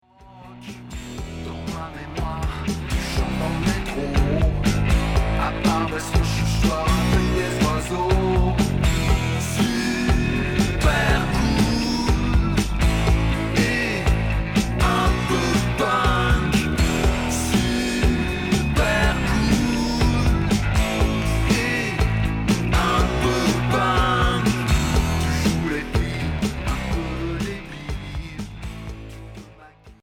Rock sur une face 45t extrait d'un LP retour à l'accueil